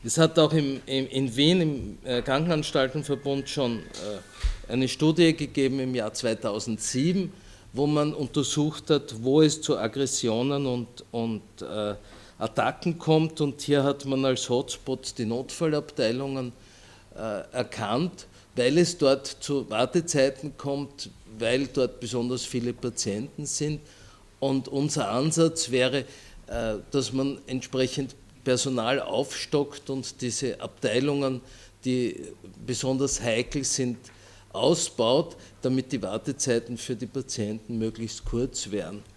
O-Töne (MP3)